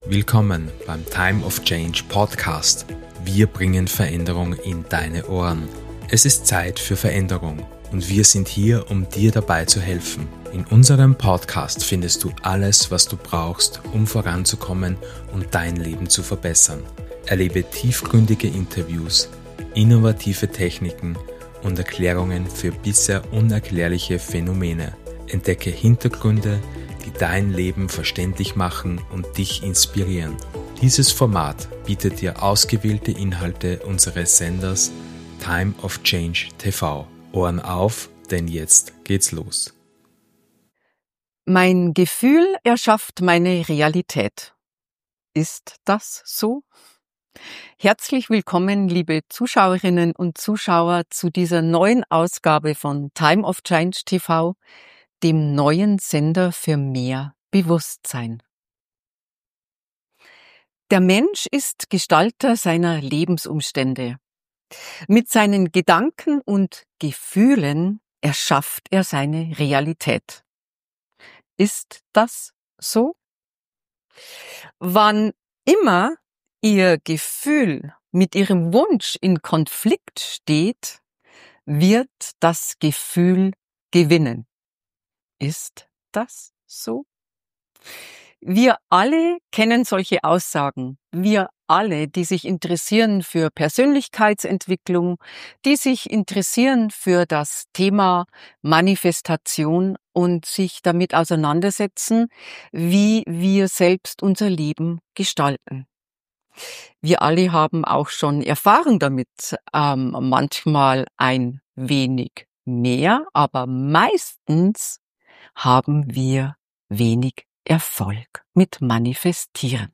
Dieses Interview wird Dich inspirieren, Deine Gefühle als kraftvolles Werkzeug zu nutzen und Dein Leben bewusst zu gestalten.